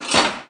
switch_4.wav